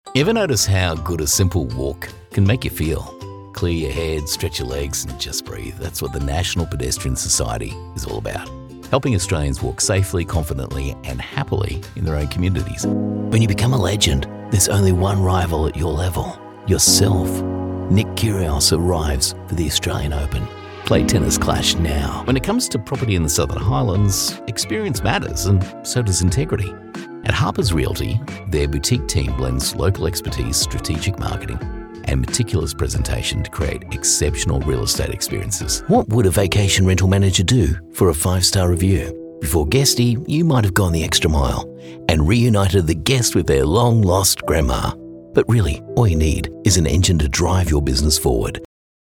Male Voice Over Talent, Artists & Actors
Adult (30-50)